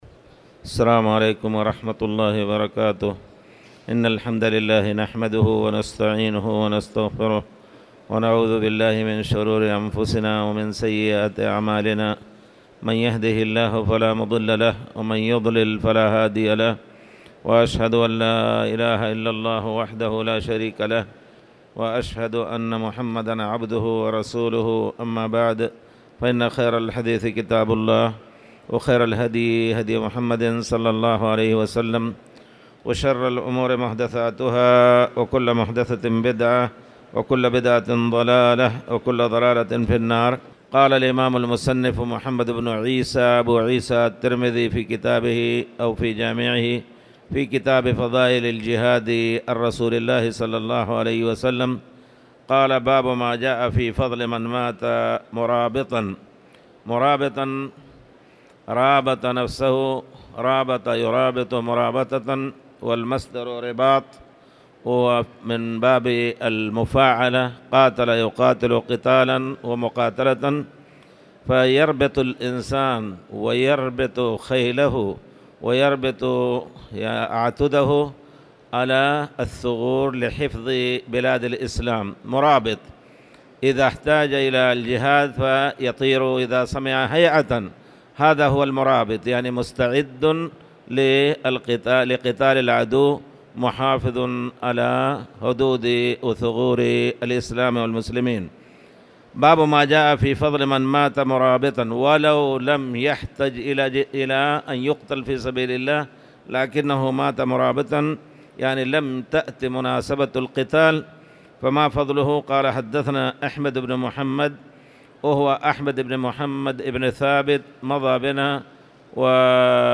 تاريخ النشر ٢٥ رجب ١٤٣٨ هـ المكان: المسجد الحرام الشيخ